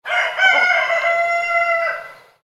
Sound Of Cockerel Crowing
A rooster crows loudly on a rural farm early in the morning, creating an authentic countryside atmosphere.
Genres: Sound Effects
Sound-of-cockerel-crowing.mp3